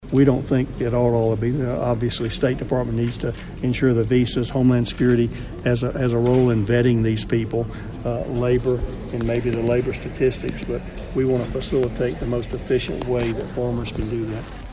Kansas State welcomed U.S. Agriculture Secretary Sonny Perdue to campus Thursday as the 179th speaker for the Landon Lecture series.